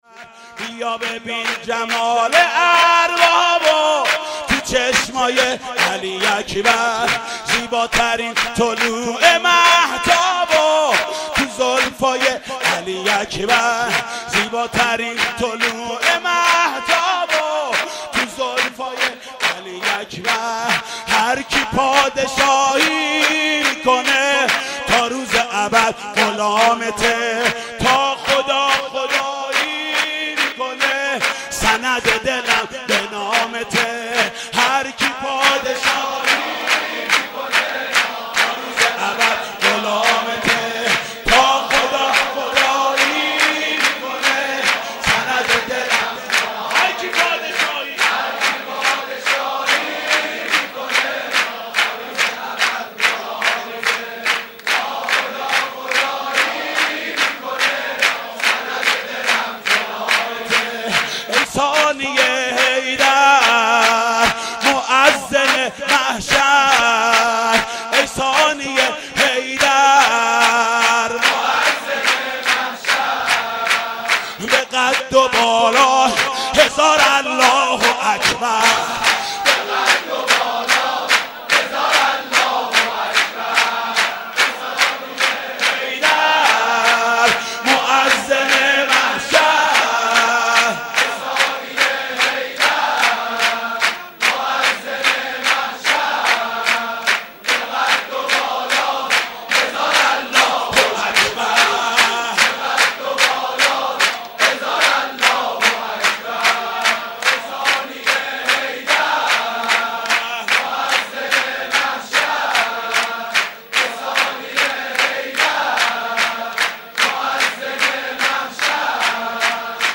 سرود: بیا ببین جمال اربابو تو چشمای علی اکبر